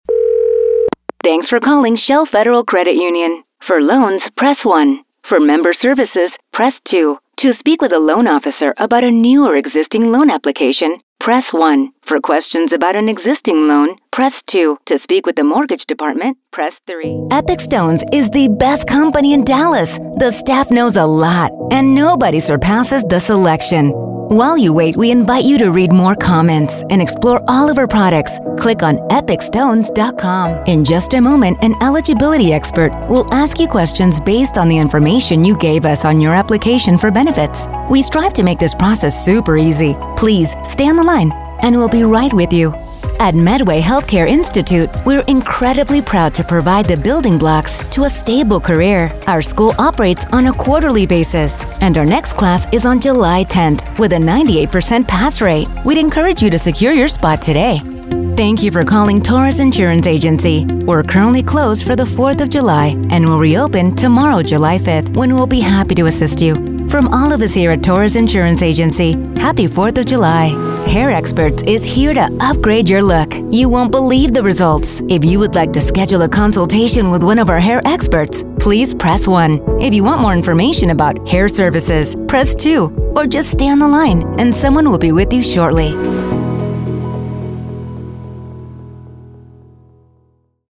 Female Voice Over, Dan Wachs Talent Agency.
Sassy, Sincere, Confident
IVR